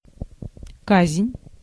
Ääntäminen
Ääntäminen US : IPA : ['pʌn.ɪʃ.mənt]